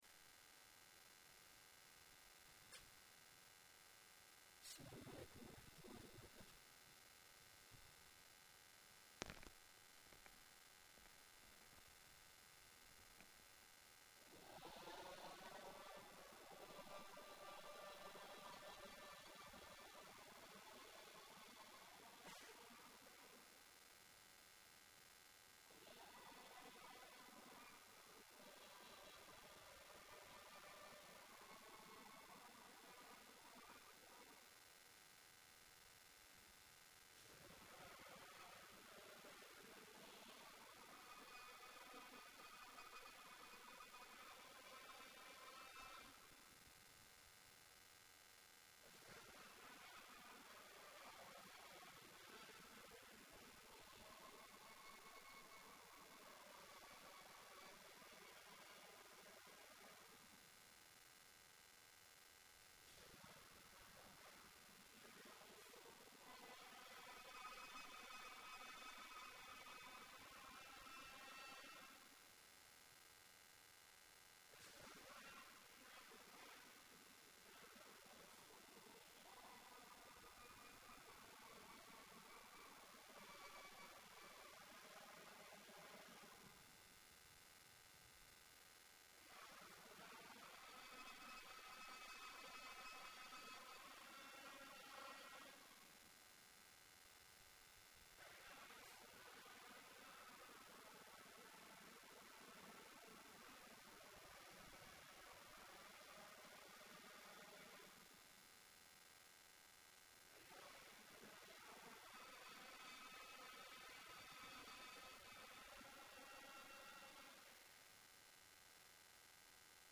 الخطبه